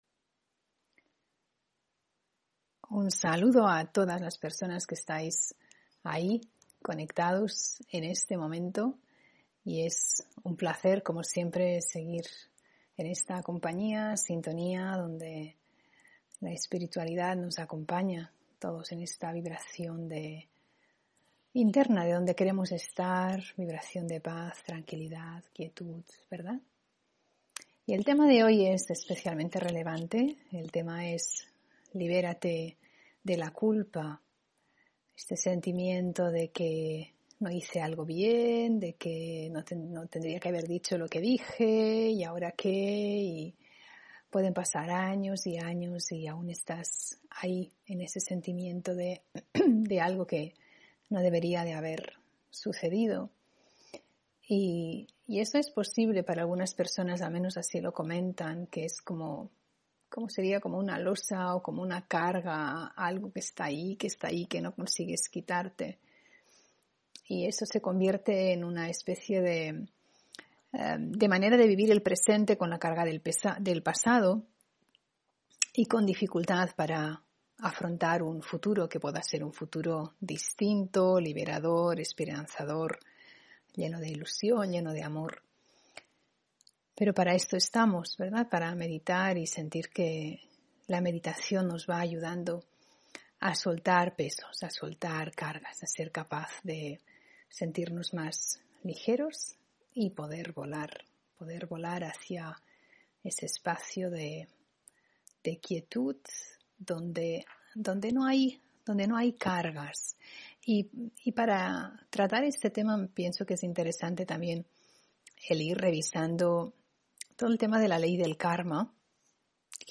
Meditación y conferencia: Libérate de la culpa (11 Noviembre 2021)